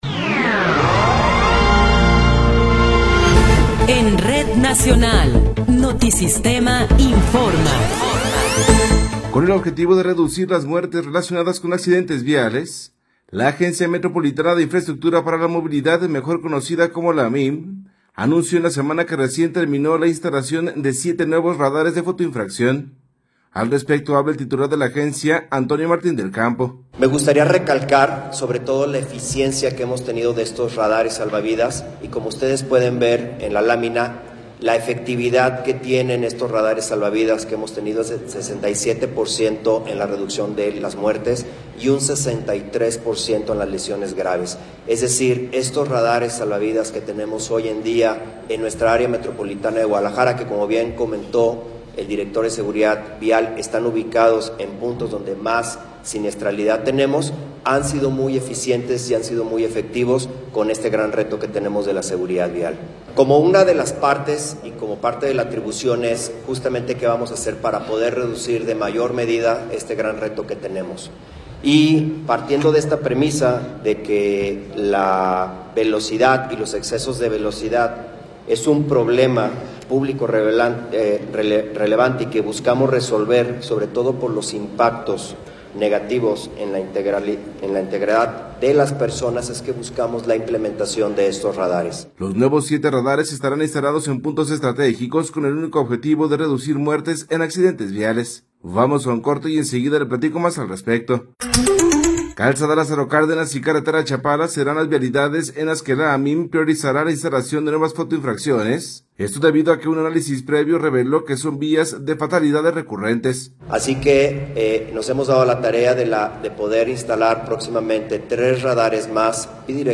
Resumen informativo Notisistema, la mejor y más completa información cada hora en la hora.